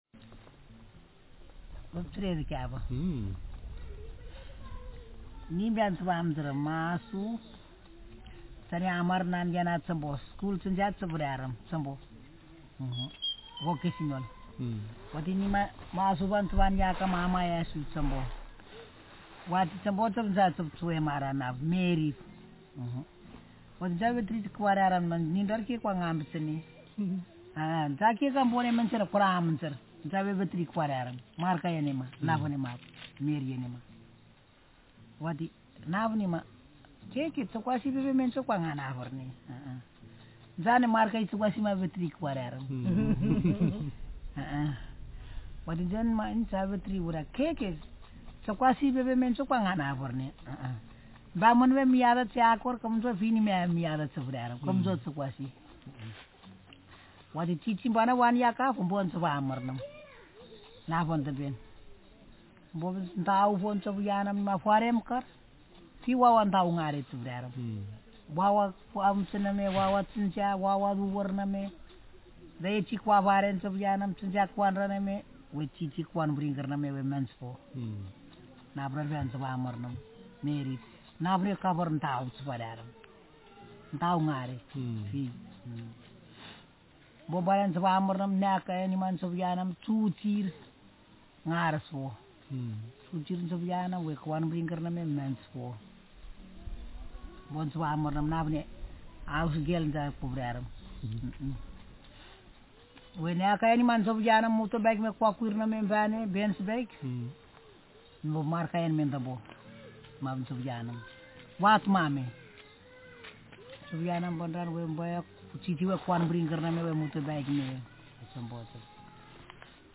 Speaker sexf
Text genrepersonal narrative